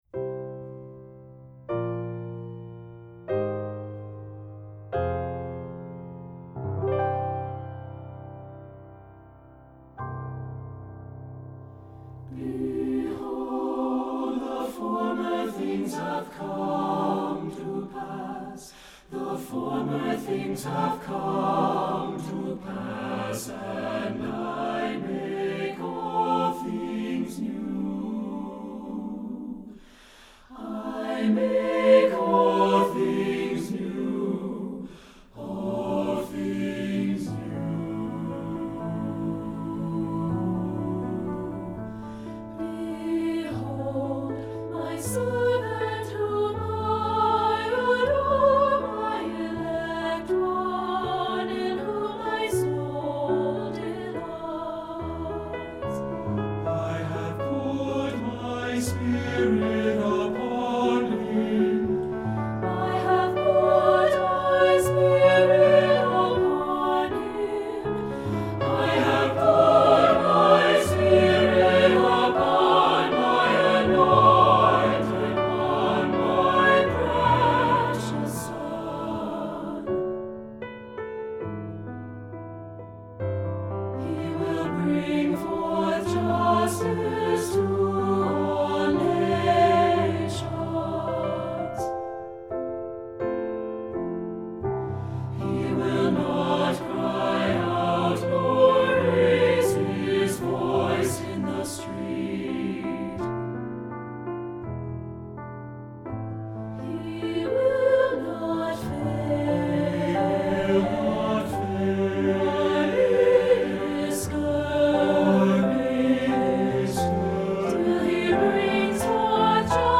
Voicing: SATB and Piano